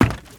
High Quality Footsteps
STEPS Wood, Creaky, Run 19.wav